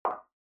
click-1.mp3